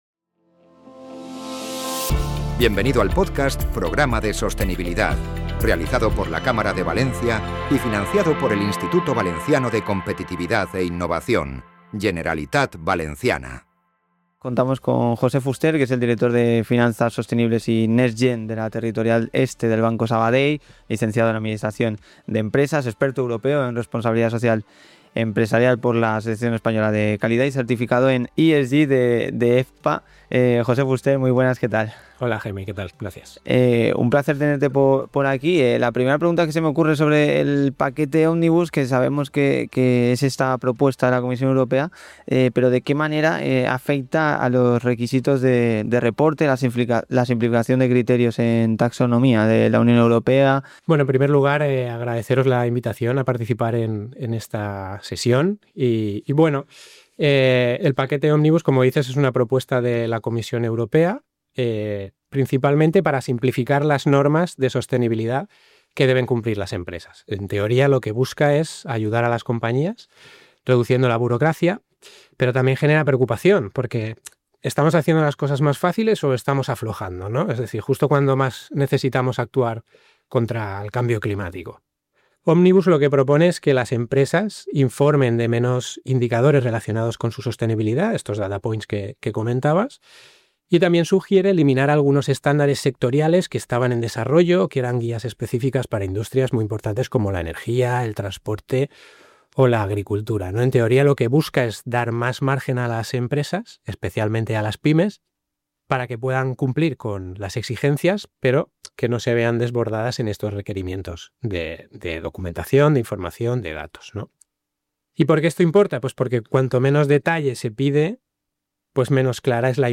Entrevista Banco Sabadell